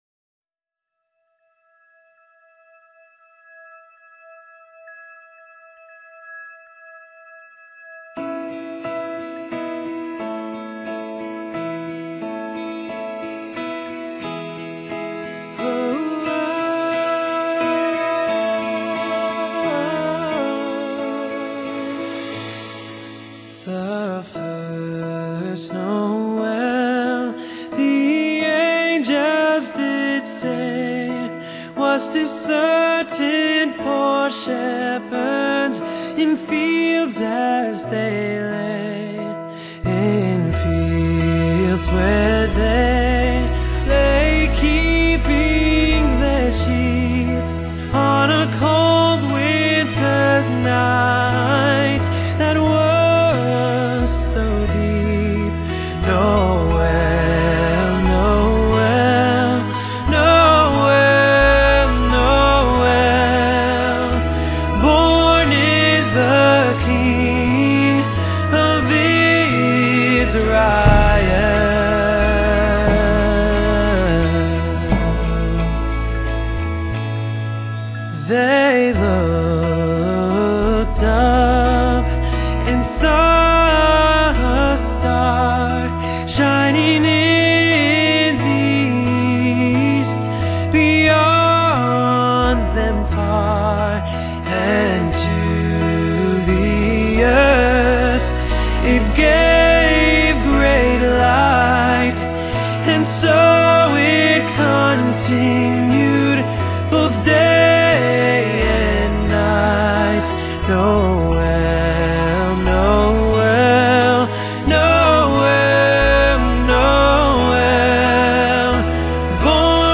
就讓他的溫暖歌聲  許我們一個陽光煦煦的耶誕！